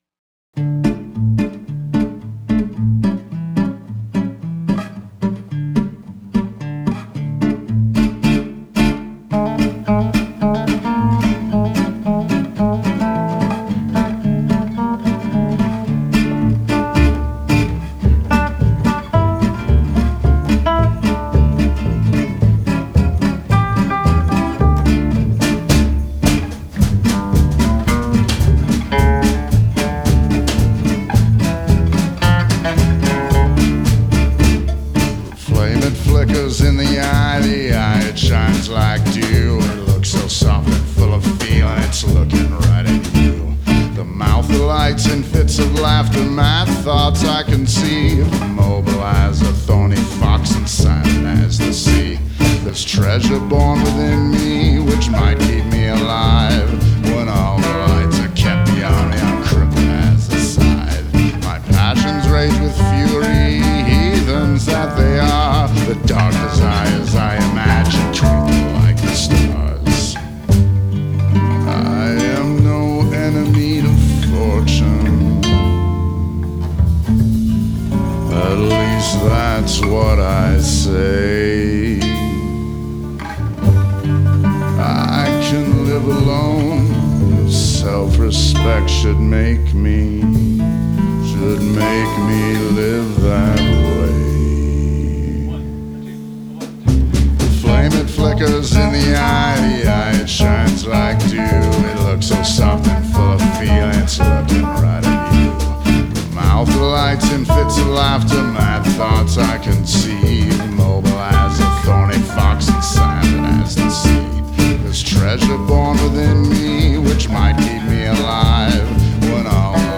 original music for the stage adaptation